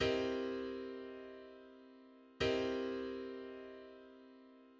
Dominant seventh raised ninth vs dominant seventh split third chord.[5]